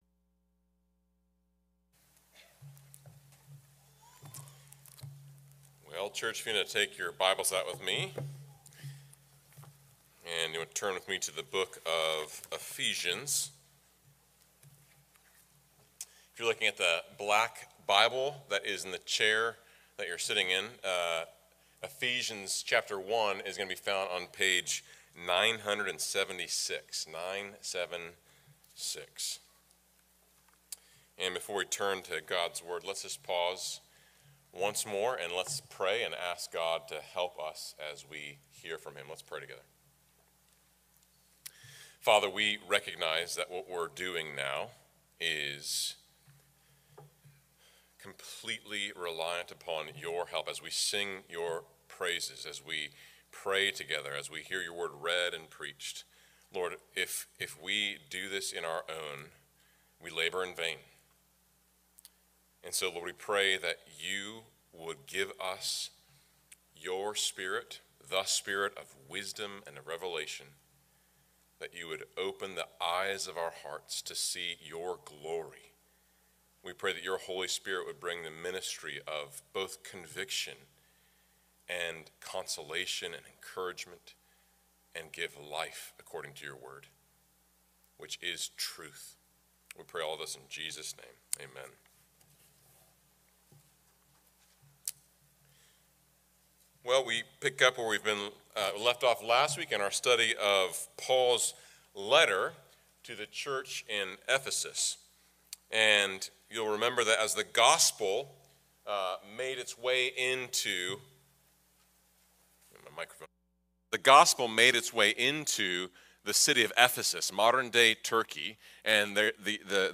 FBC Sermons